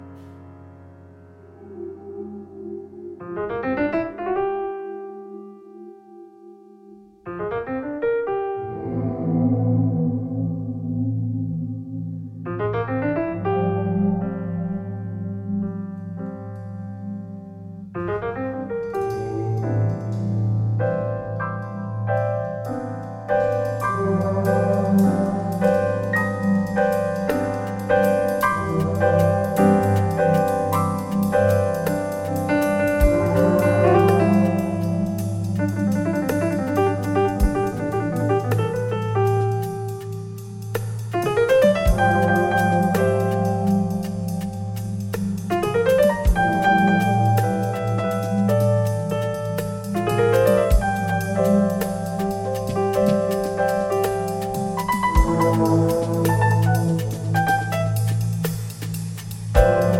Live In Istanbul